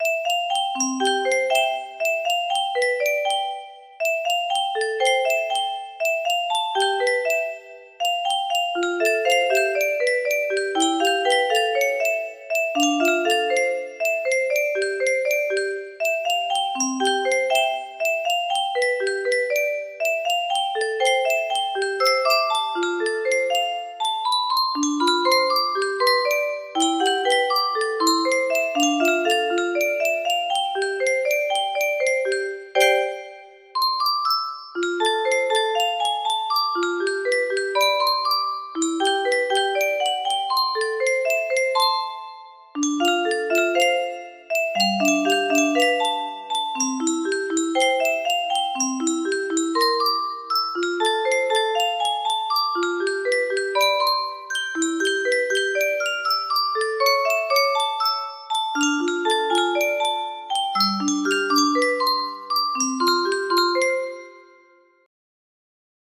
Oscar music box melody
Grand Illusions 30 (F scale)